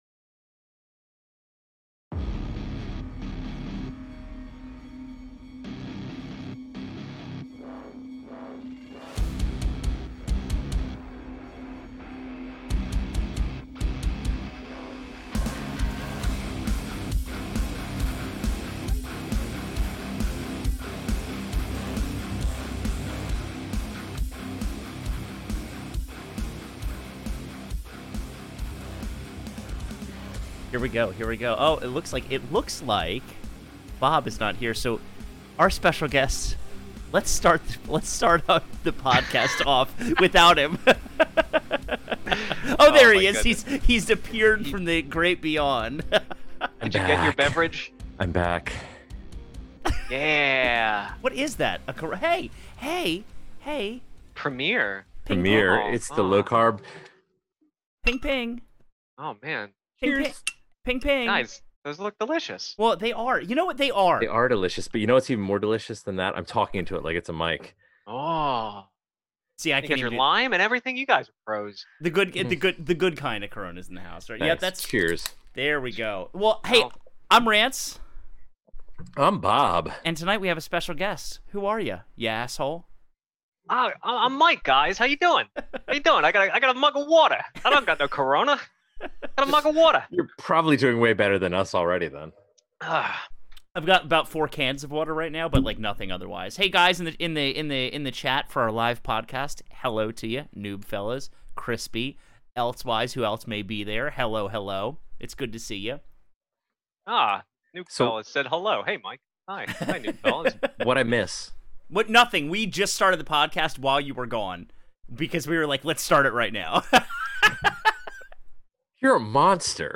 There's a good amount of cursing in this episode.